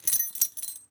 foley_keys_belt_metal_jingle_11.wav